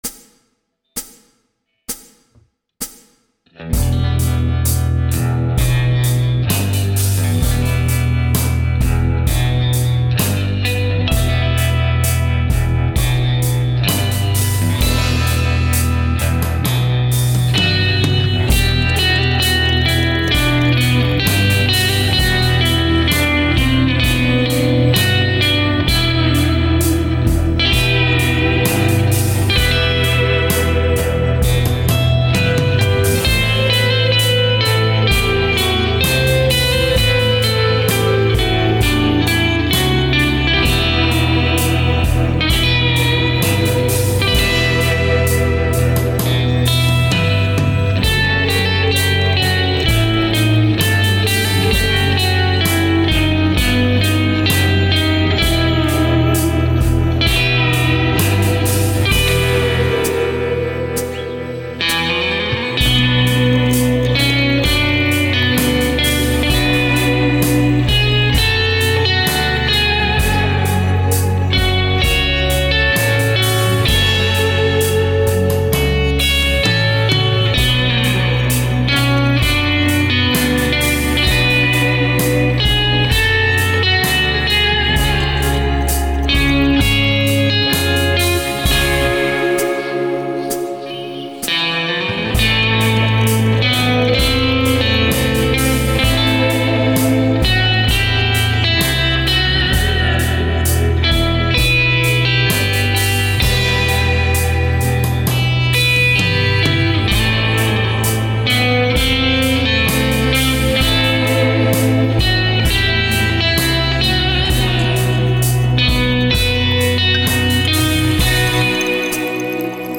The rythym guitar has Reverb and Vibrato
The lead guitar just has Reverb
The reverb and vibrato sound great.
Love that tone and bigsby-sounding trem work!
The reverb is all coming from the ReVibe